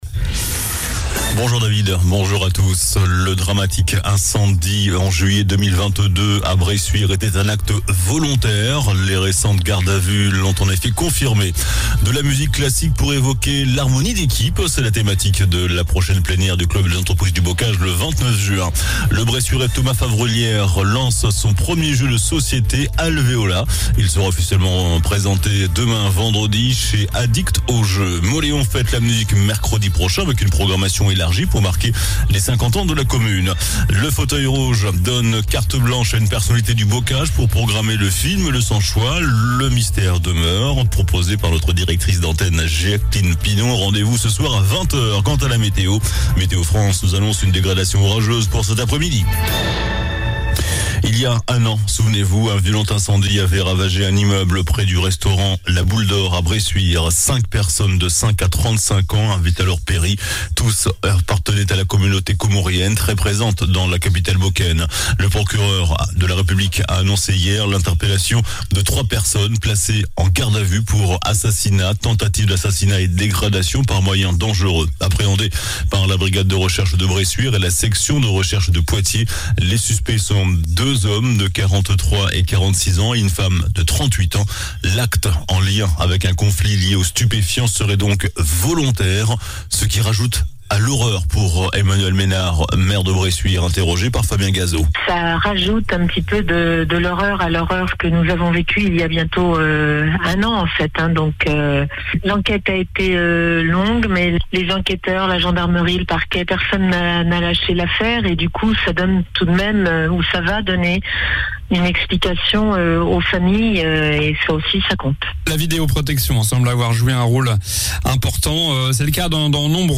JOURNAL DU JEUDI 15 JUIN ( MIDI )